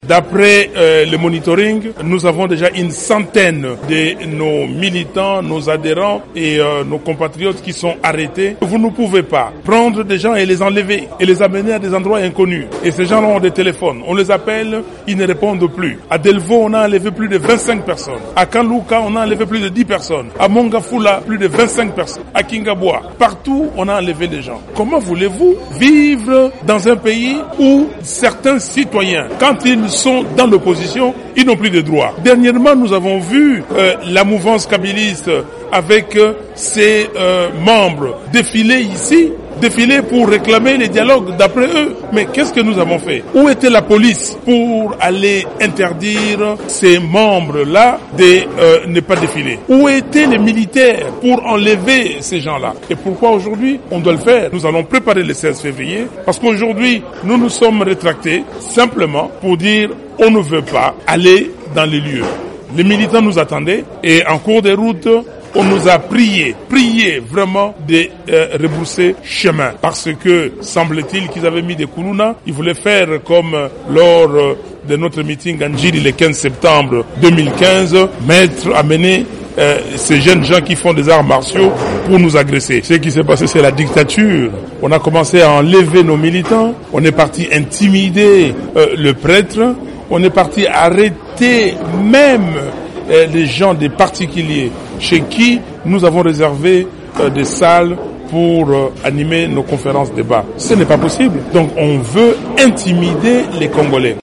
Martin Fayulu au micro de Radio Top Congo